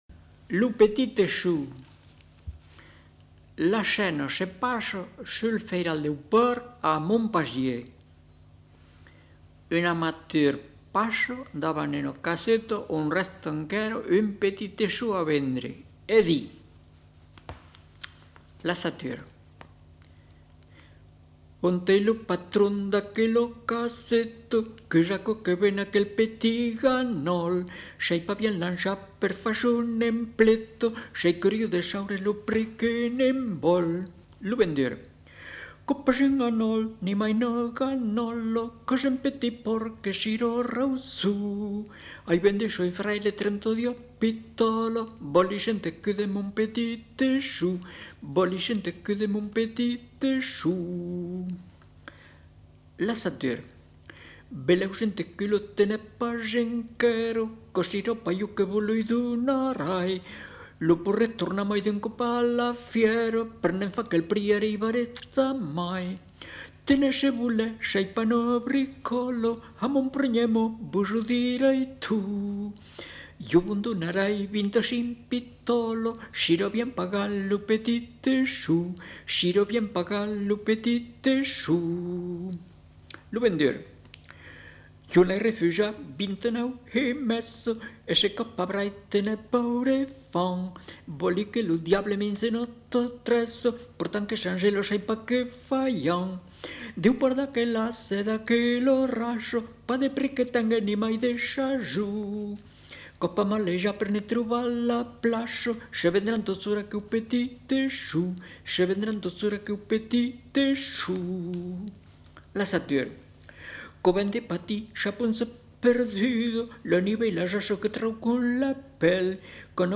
Aire culturelle : Périgord
Lieu : Lolme
Genre : chant
Effectif : 1
Type de voix : voix d'homme
Production du son : chanté
Timbre de l'air : J'ai du bon tabac
Ecouter-voir : archives sonores en ligne